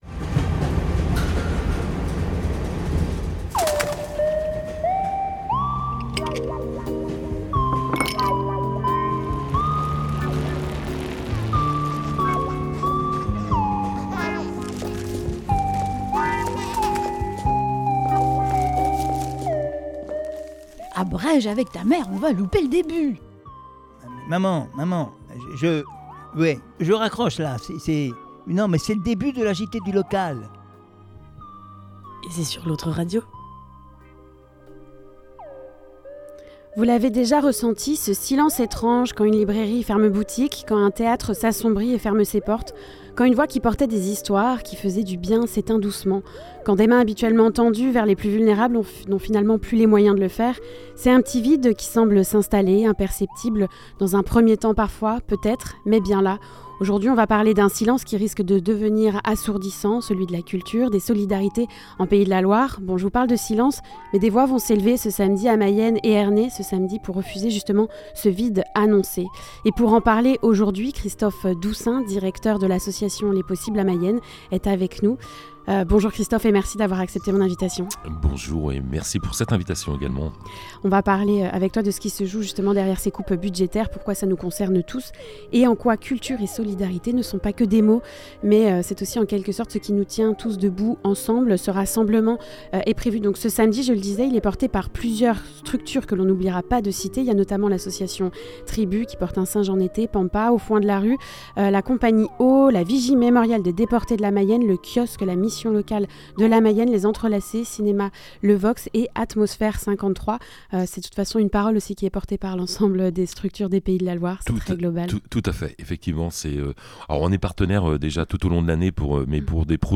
Le micro trottoir de la semaine